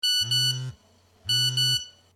cell_ring_0.ogg